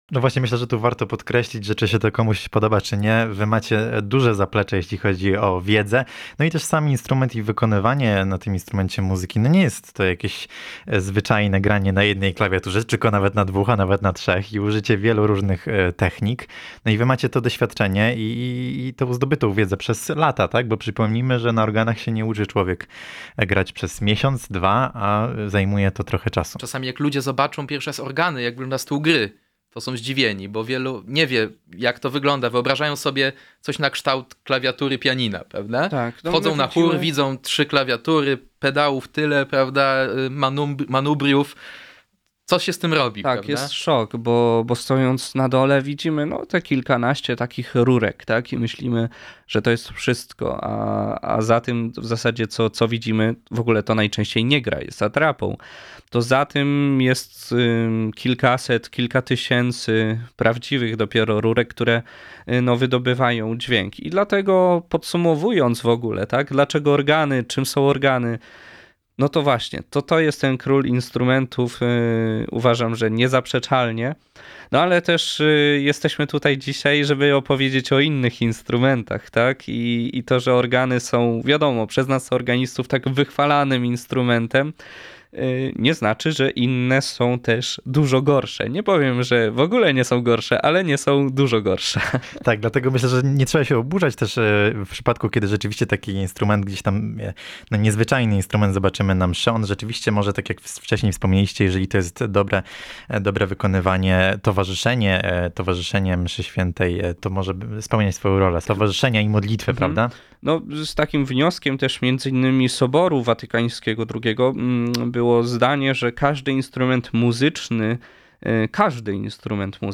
organiści.